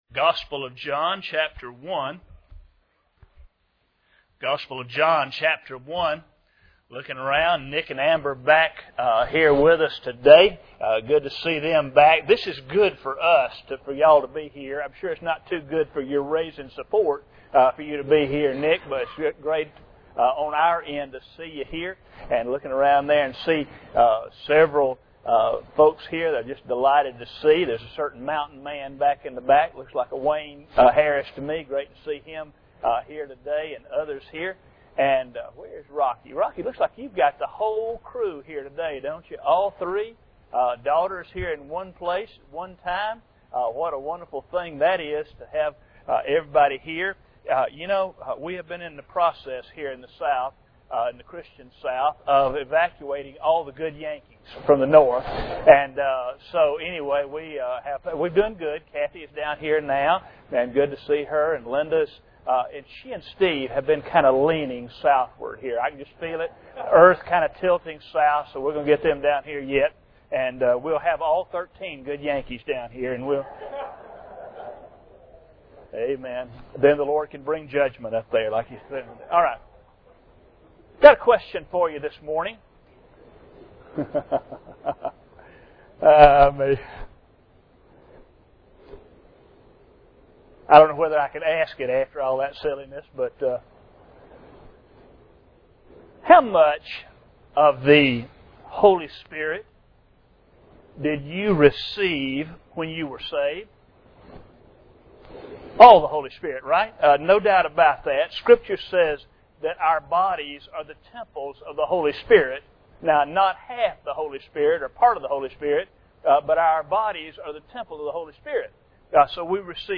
John 1:14-17 Service Type: Sunday Morning Bible Text